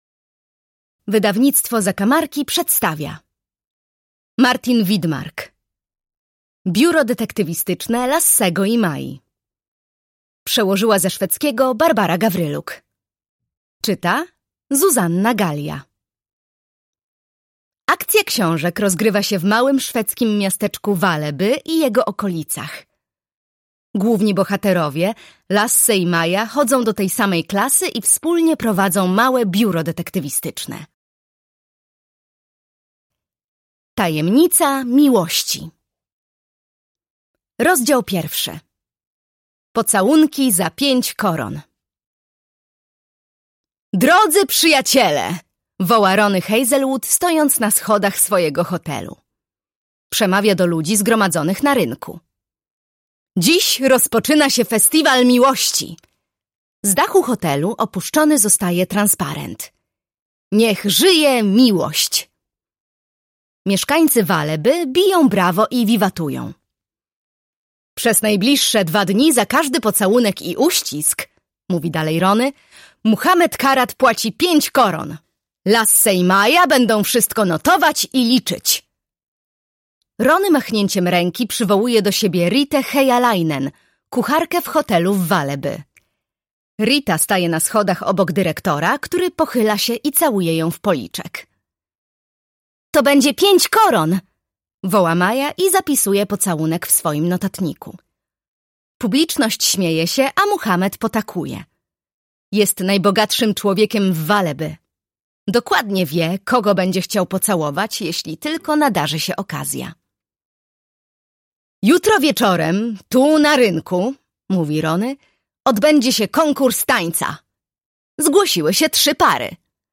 Biuro Detektywistyczne Lassego i Mai. Tajemnica miłości - Martin Widmark - audiobook